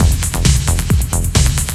TECHNO125BPM 9.wav